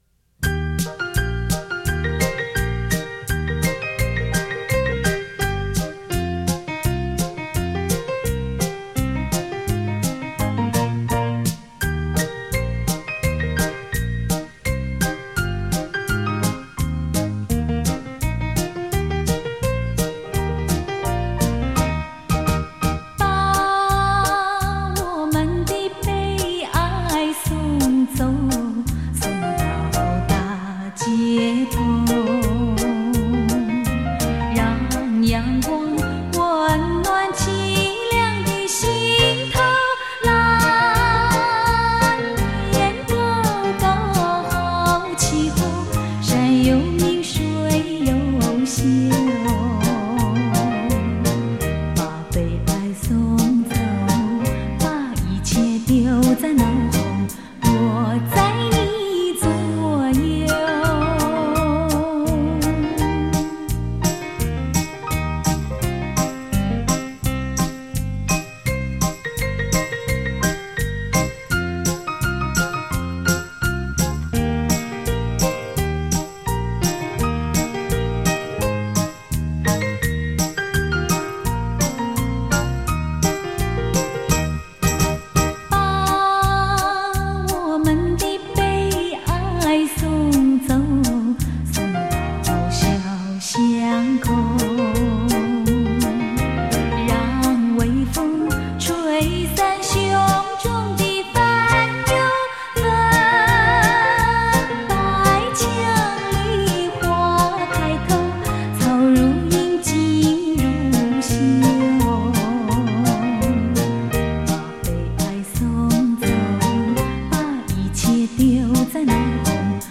双钢琴现场演奏，熟悉好歌精选